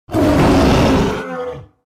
Download Tiger sound effect for free.
Tiger